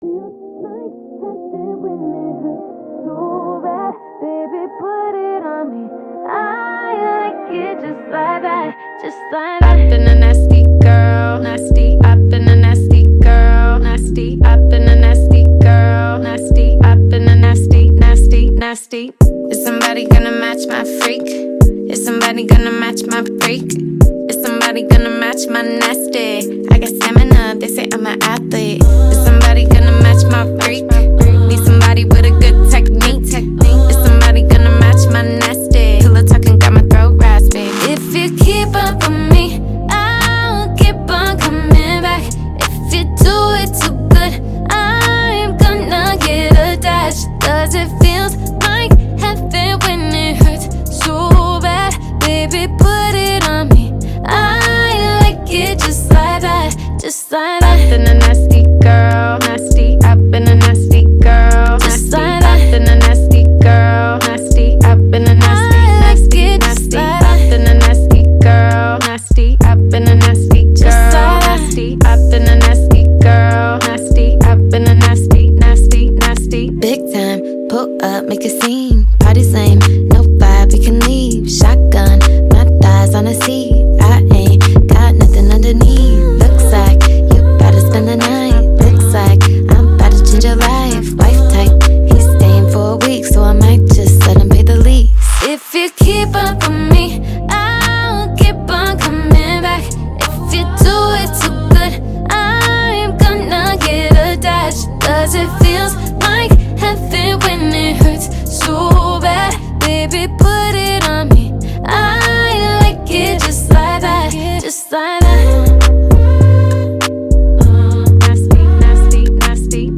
BPM100
Audio QualityPerfect (Low Quality)